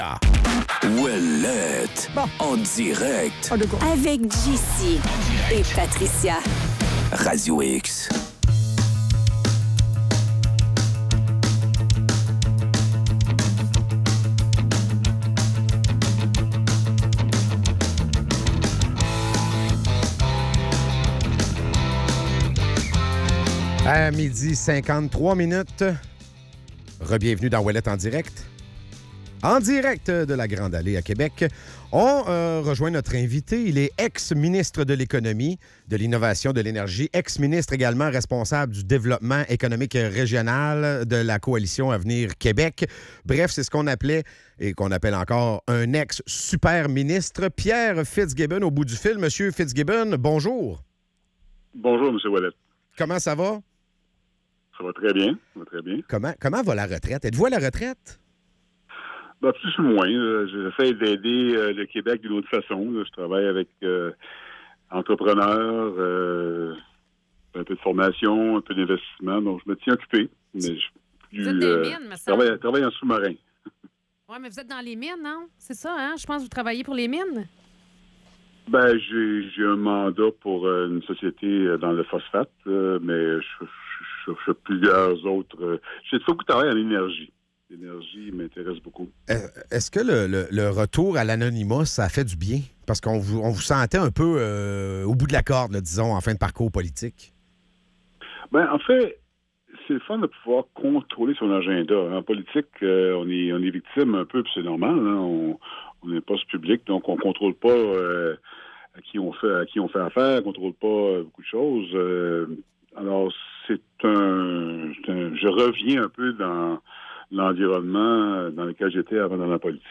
En entrevue, Pierre Fitzgibbon.